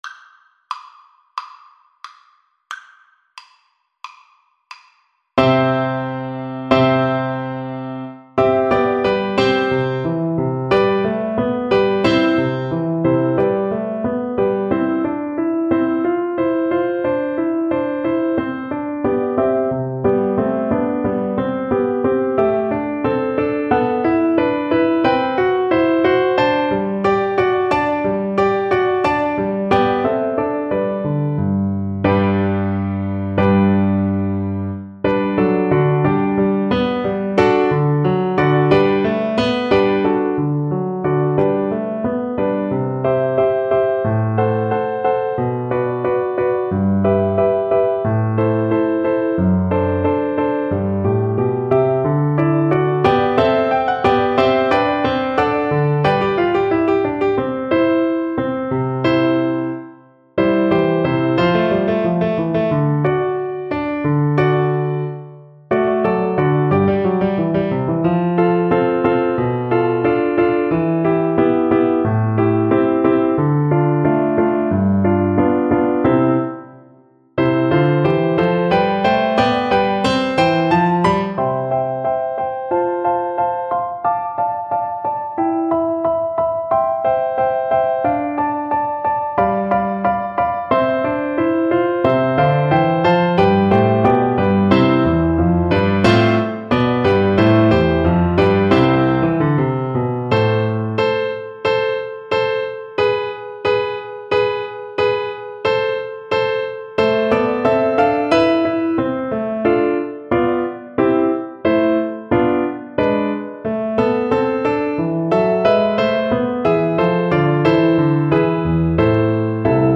Flute
4/4 (View more 4/4 Music)
C major (Sounding Pitch) (View more C major Music for Flute )
= 90 Allegro (View more music marked Allegro)
C5-D7
Classical (View more Classical Flute Music)